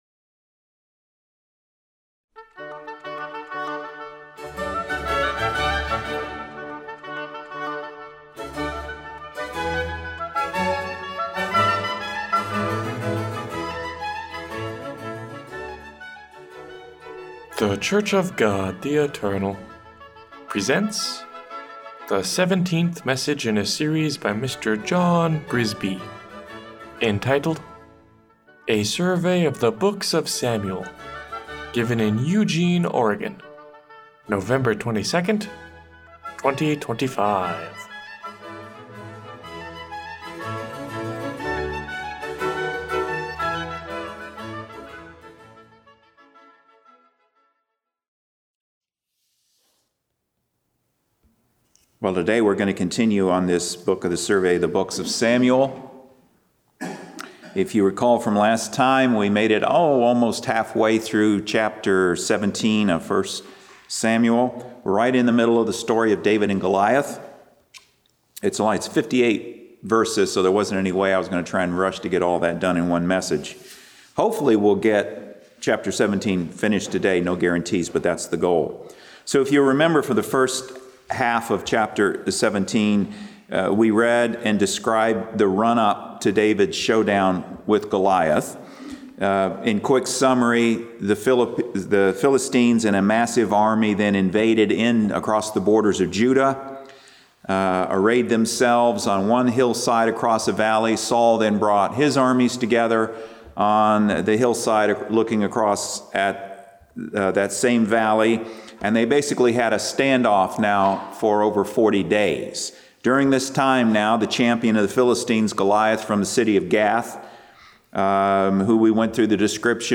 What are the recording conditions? This section catalogs weekly Sabbath sermons presented in Eugene, Oregon for the preceding twelve month period, beginning with the most recent.